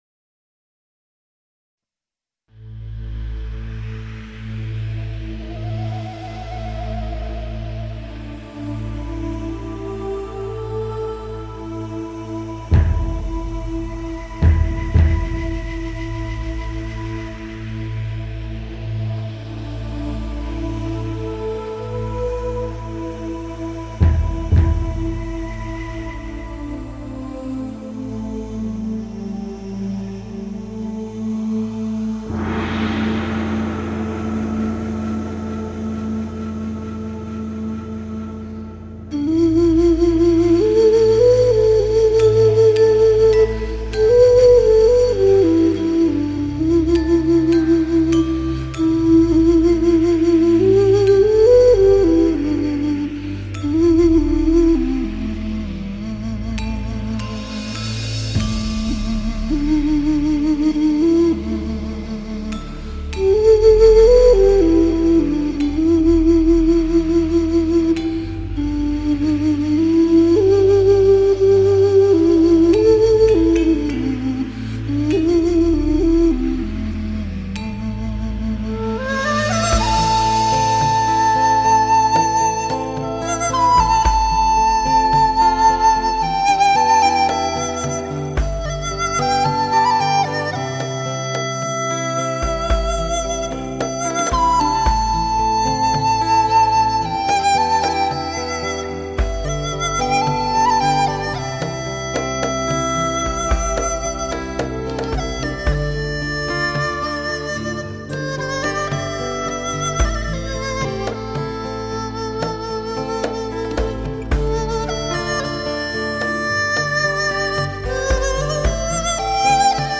二胡、琵琶、葫芦丝、埙、唢呐和众多西洋乐器与笛子相辉映，交织出一幅令人心旷神怡的音乐画面。
前奏电子合成的几下大鼓沉实有劲，爽快利落。